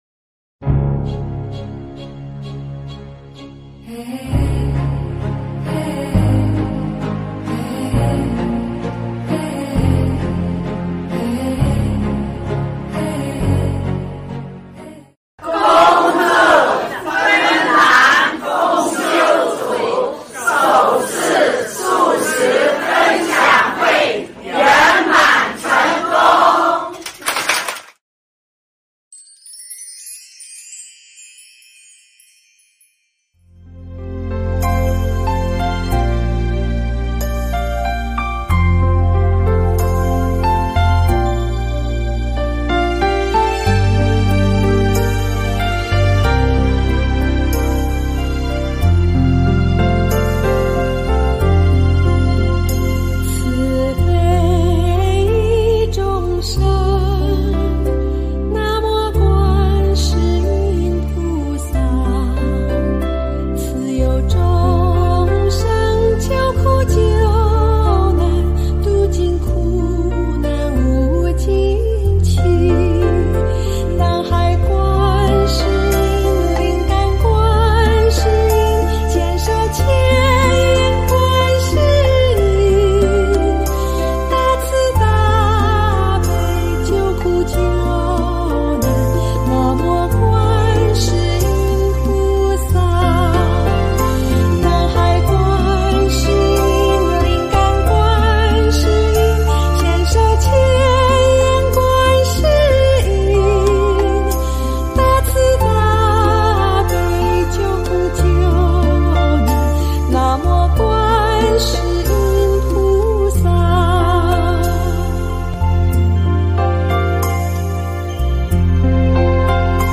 音频：芬兰赫尔辛基，素食视频分享会！